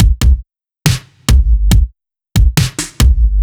Index of /musicradar/french-house-chillout-samples/140bpm/Beats
FHC_BeatA_140-03_KickSnare.wav